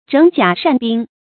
整甲繕兵 注音： ㄓㄥˇ ㄐㄧㄚˇ ㄕㄢˋ ㄅㄧㄥ 讀音讀法： 意思解釋： 整頓甲胄，修理兵器。